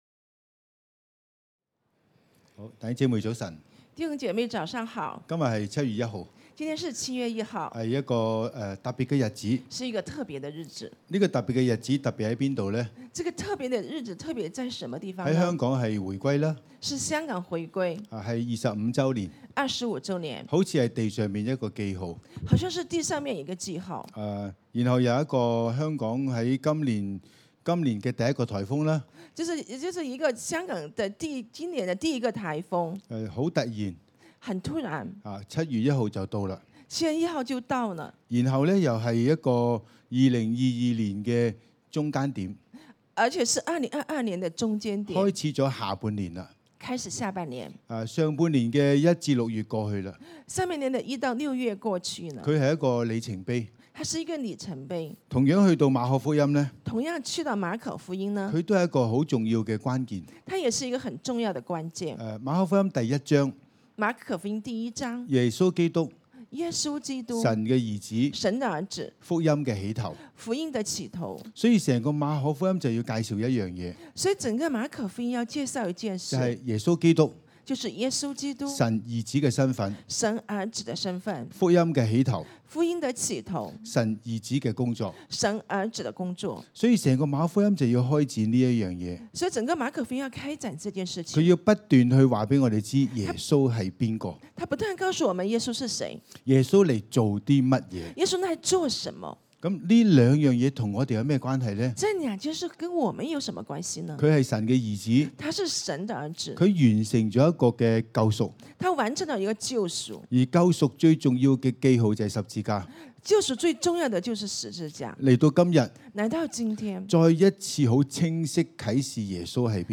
6) 祝福 吹七聲號角，歡迎聖靈，我們一同進入新年代。